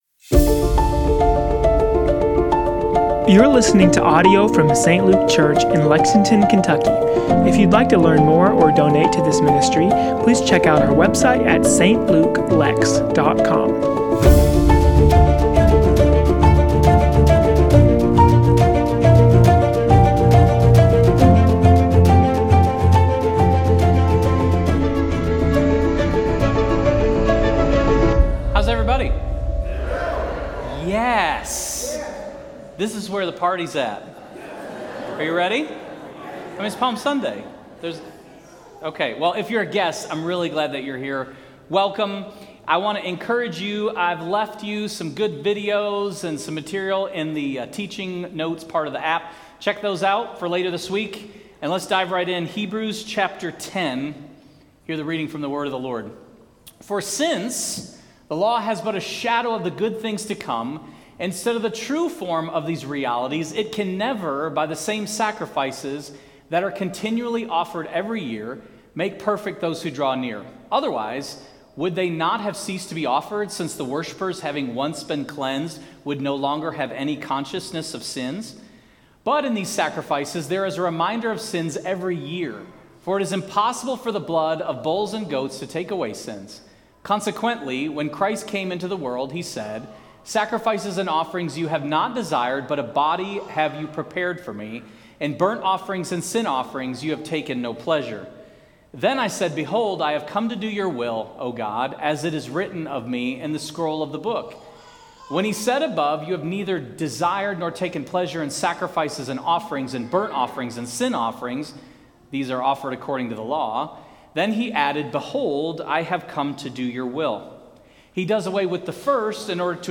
Sermon Series: Hebrews: Rediscovering Jesus